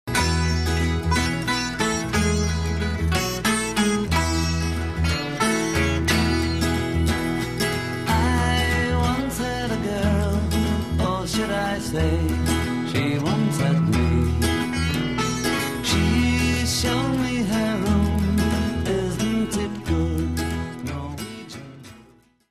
Sitar indiano